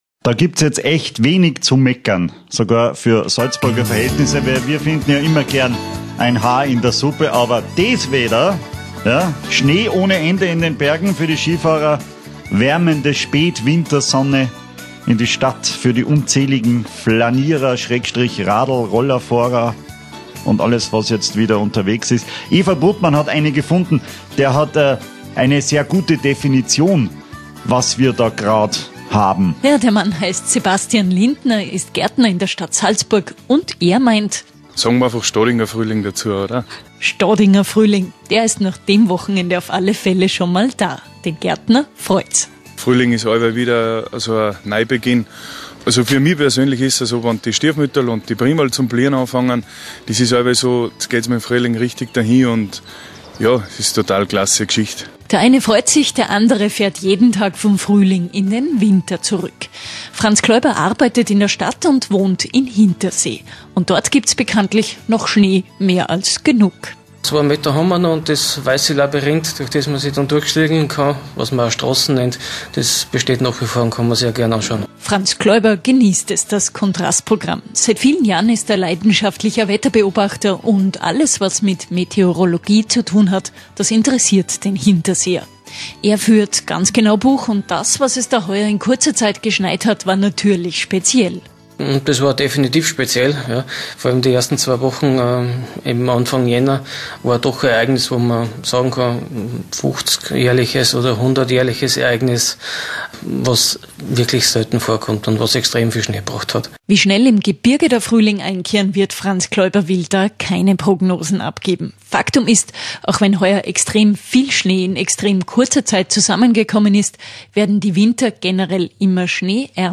18.02.19 Interview ORF Radio Salzburg / Winter  18/19